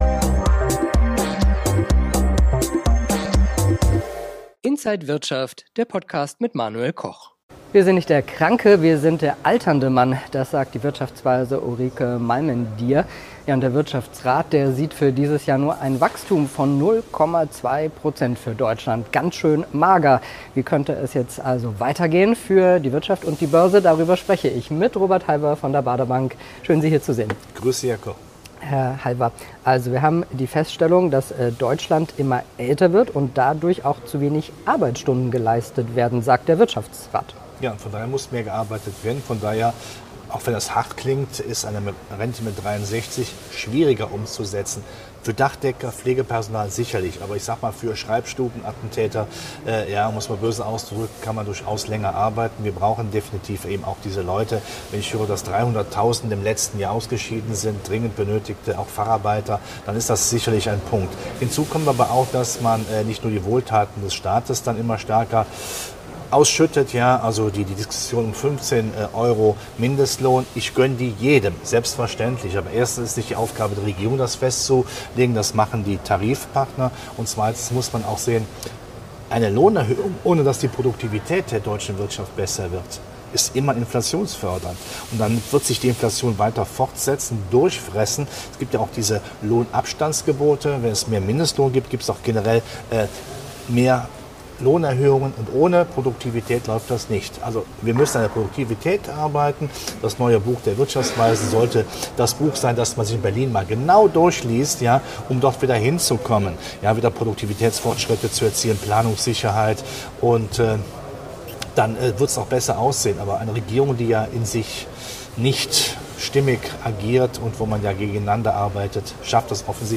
Alle Details im Interview von